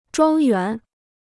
庄园 (zhuāng yuán): manoir; domaine.
庄园.mp3